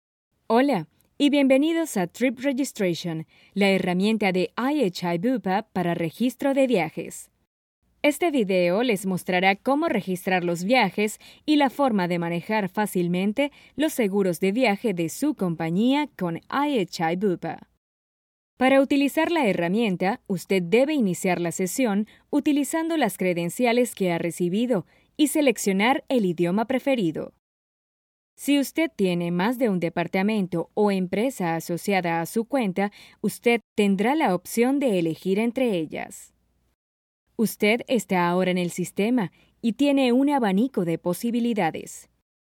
Versatile, 8 years experience. Uplifting, friendly, announcer, corporate, maternal, sexy, sensual, funny, calming, serious, informative
spanisch SĂŒdamerika
Sprechprobe: Industrie (Muttersprache):